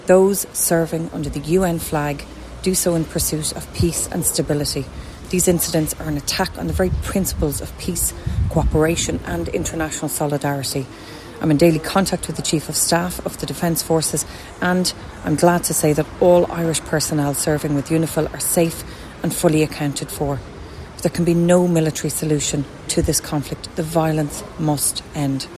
Minister for Defence Helen McEntee says Irish peacekeepers in the region are safe and accounted for but the recent escalation is deeply worrying………